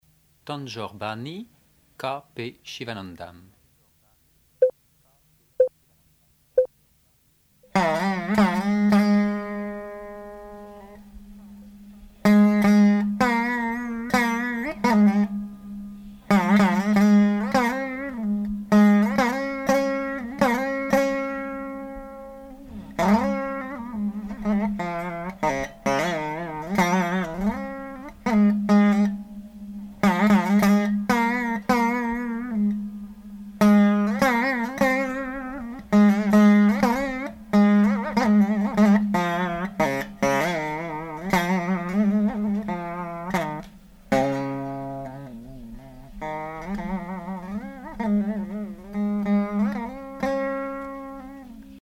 Alap (accoustique) : Kalyäni
Musique carnatique
Pièce musicale inédite